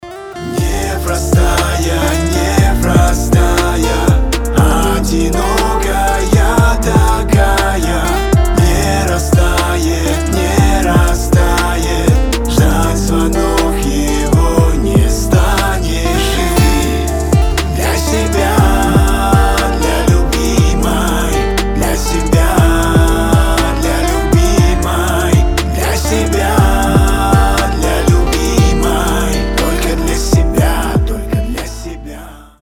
• Качество: 320, Stereo
мужской голос
воодушевляющие